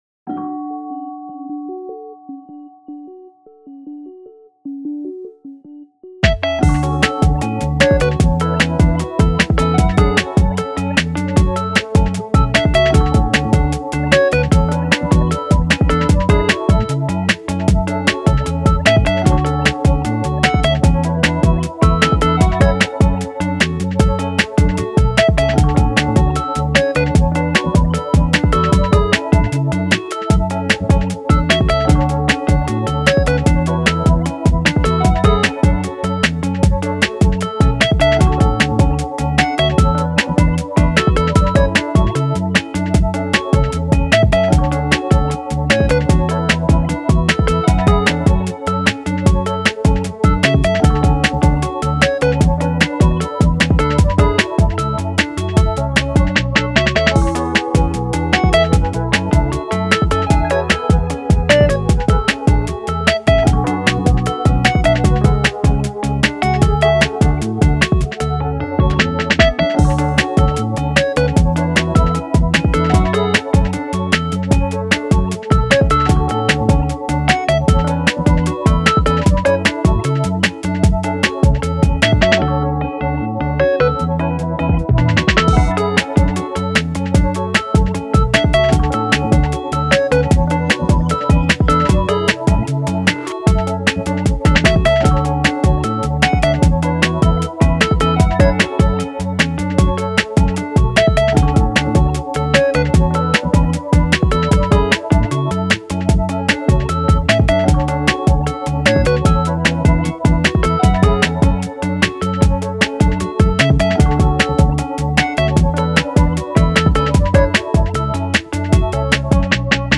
メロウ・切ない